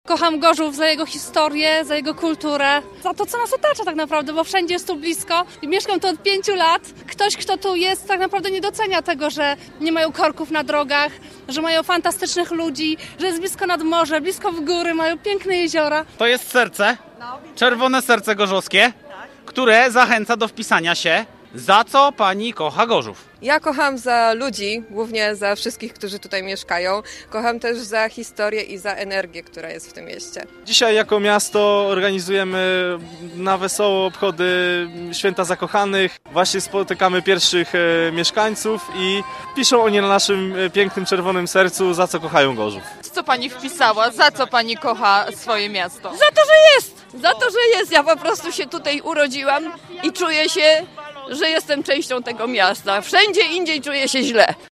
Od godziny 14.00 do 17.00, w centrum miasta, mieszkańcy mogli spotkać wielkie mobilne, czerwone serce i wpisać na nie za co kochają Gorzów.